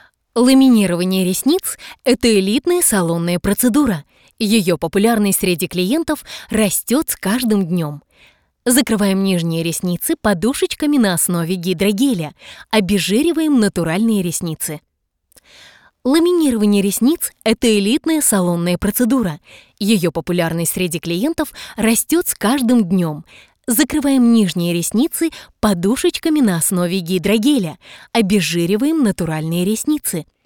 • Голос: Сопрано
• Женский
• Высокий
Закадровый текст - Тепло